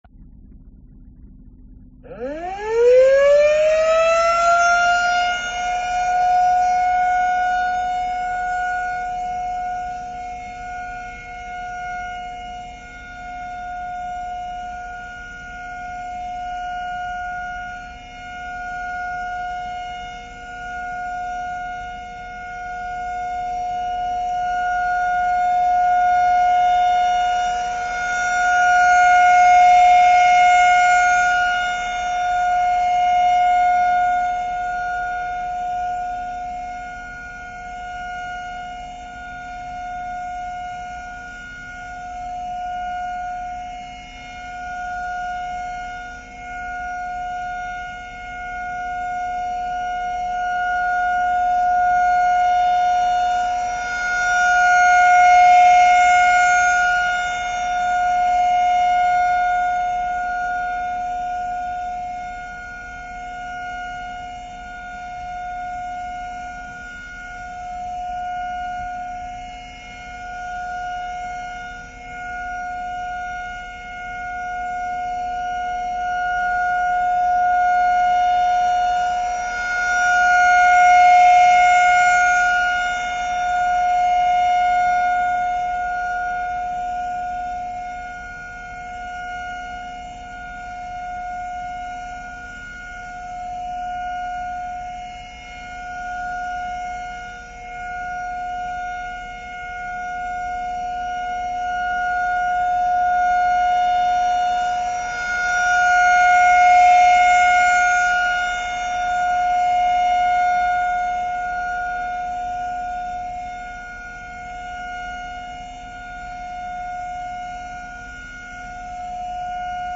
Tornado Siren Tone
Long, steady siren in one tone for three minutes.
Tornado-Siren.mp3